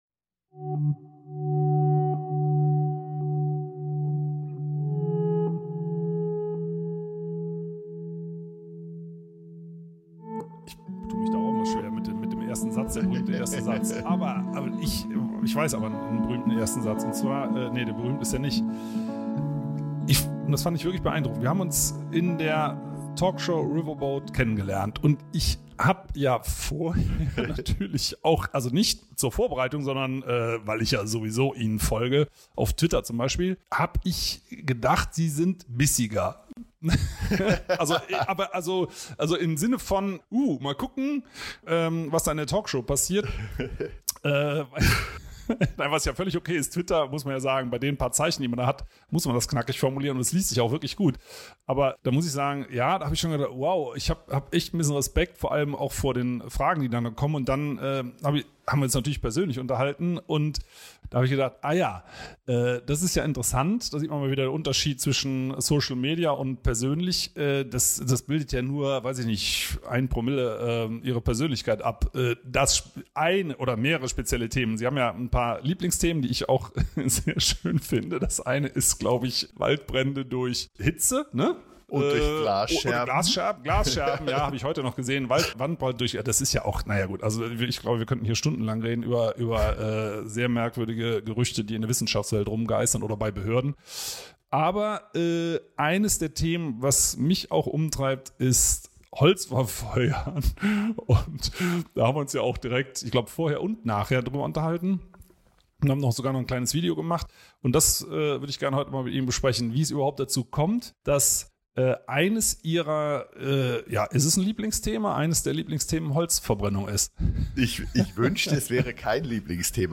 Heute spricht Peter Wohlleben mit dem Meteorologen und Moderator Jörg Kachelmann über Holzverbrennung. Ist Holzverbrennung wirklich so grün wie ihr Image?